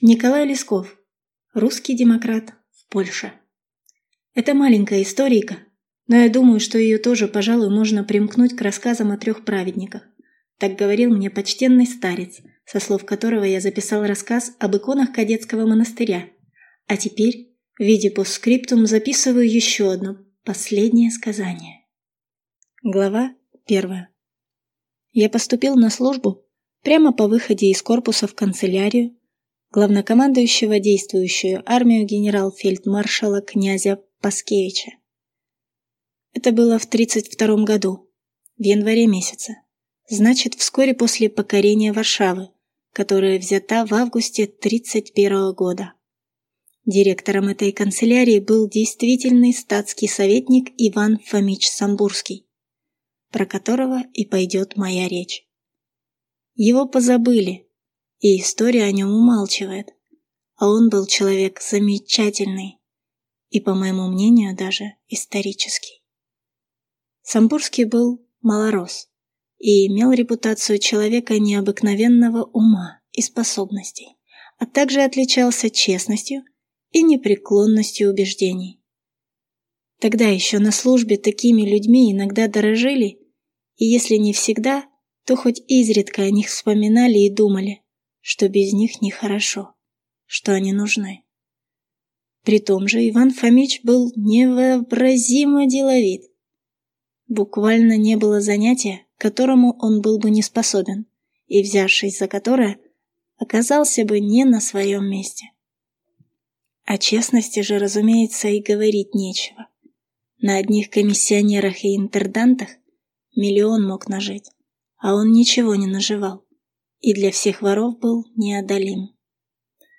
Аудиокнига Русский демократ в Польше | Библиотека аудиокниг